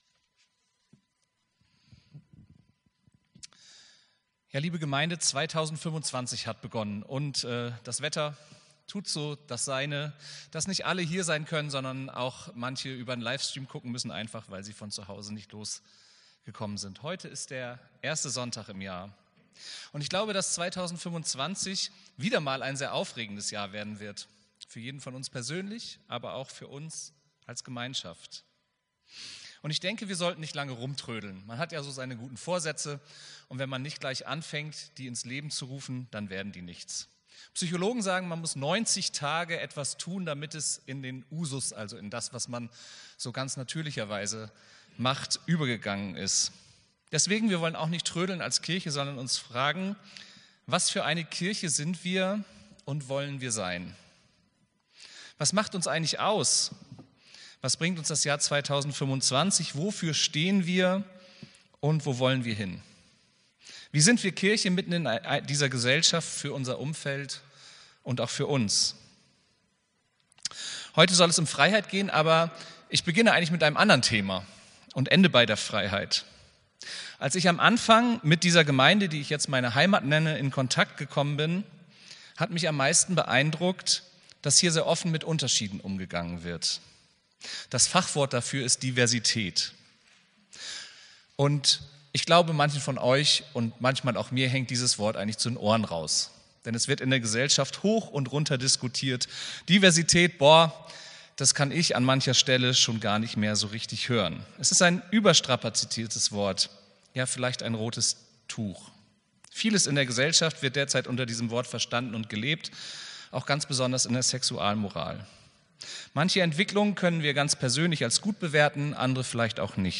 Predigt vom 05.01.2025